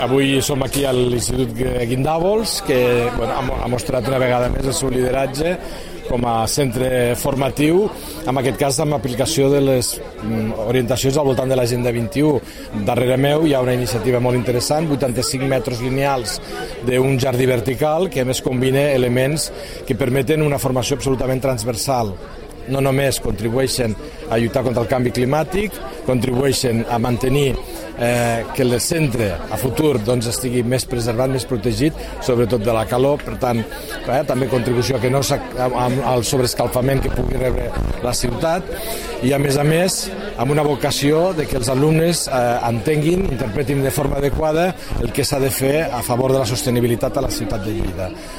tall-de-veu-del-tinent-dalcalde-felix-larrosa-sobre-el-compromis-de-linstitut-guindavols-amb-lagenda-21-escolar